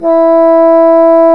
Index of /90_sSampleCDs/Roland L-CDX-03 Disk 1/WND_Bassoons/WND_Bassoon 2
WND BASSOO0H.wav